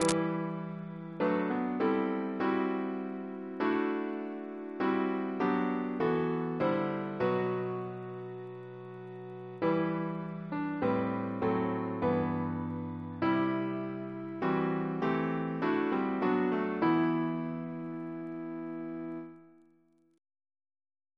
Double chant in E minor Composer